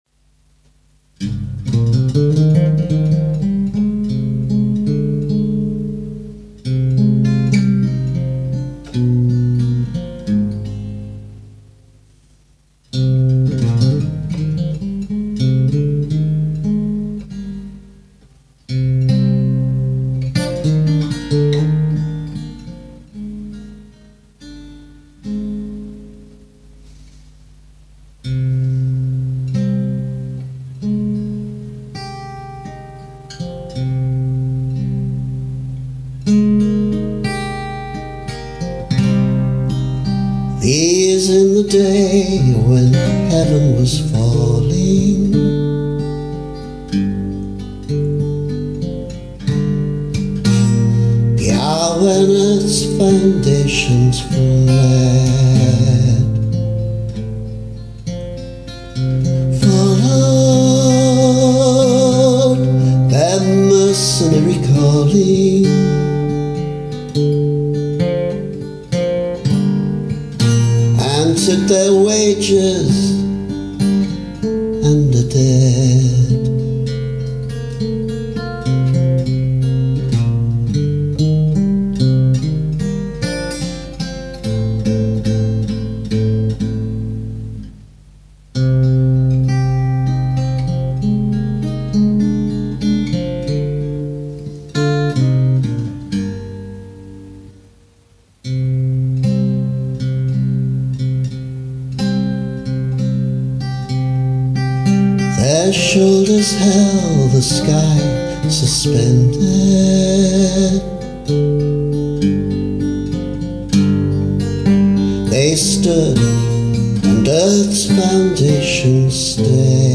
An older version with much better vocal: